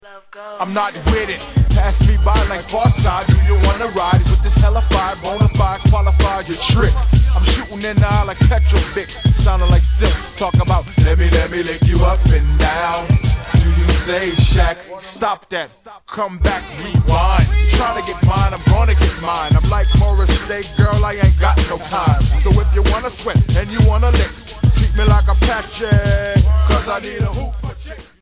celebrity singers